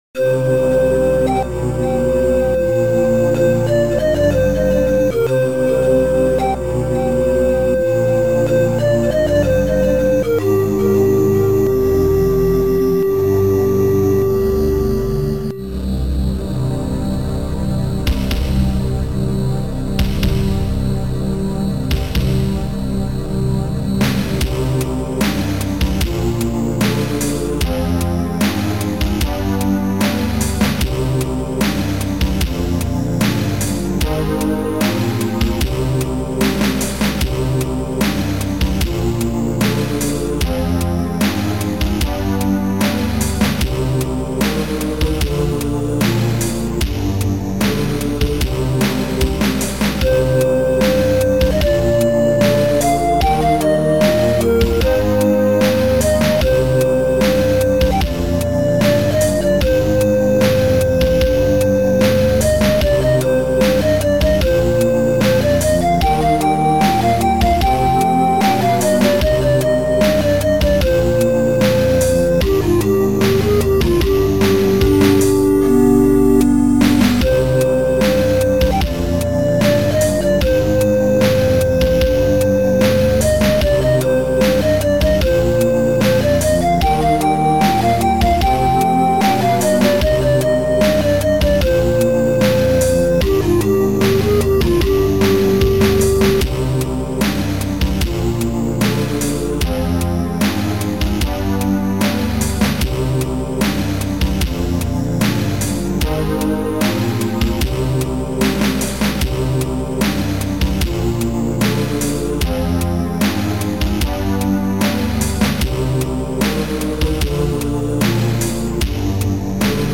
Sound Format: Noisetracker/Protracker
Sound Style: Ambient